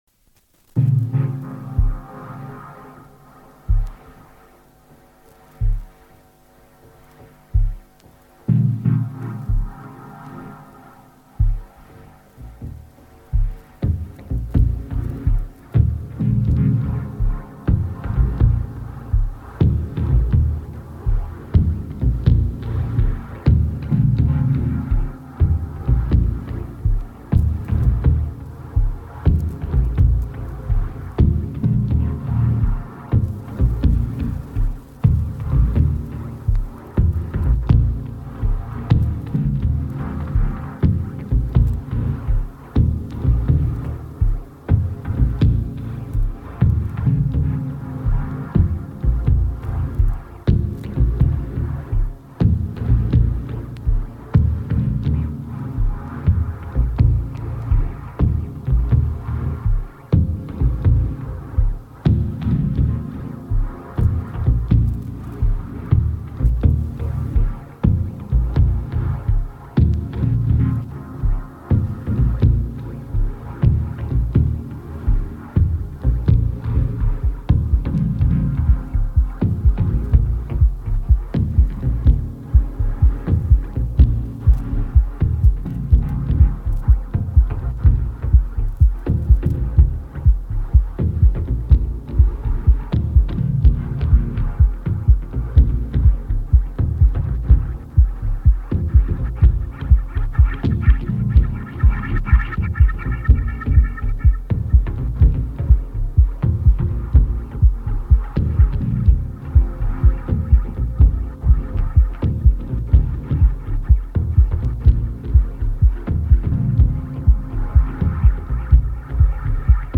Basic Dub DJ Mix